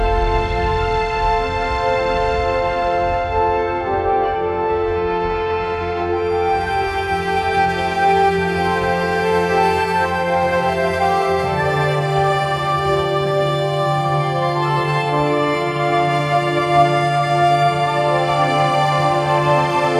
Generate music from a prompt or melody